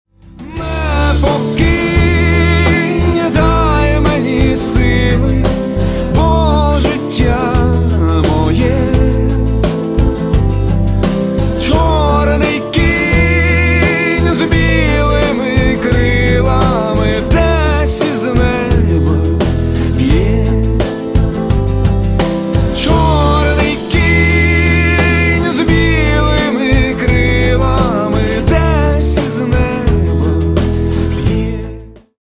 грустные # спокойные